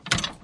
冲击、撞击、摩擦 工具 " 拖拉机摇臂
Tag: 工具 工具 崩溃 砰的一声 塑料 摩擦 金属 冲击